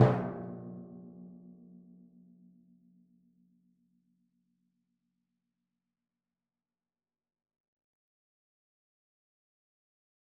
Timpani2_Hit_v4_rr2_Sum.wav